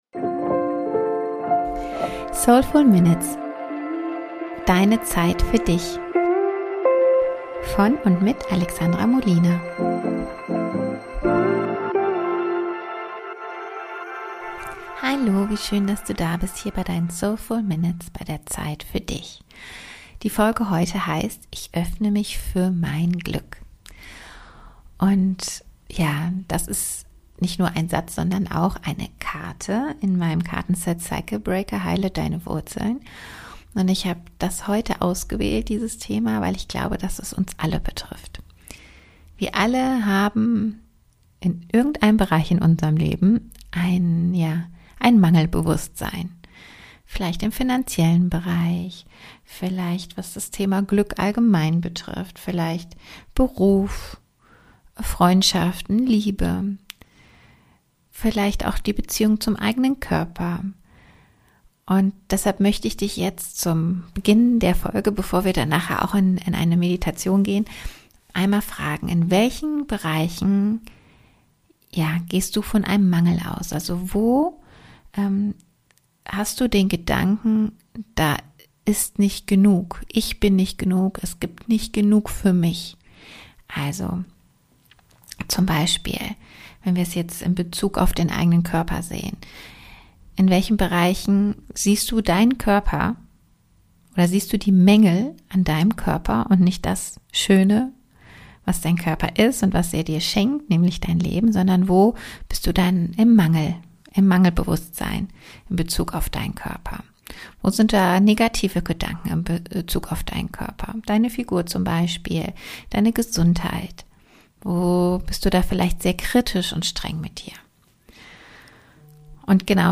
Ich habe eine kleine Meditation für dich - wir wollen uns heute für unser Glück öffnen.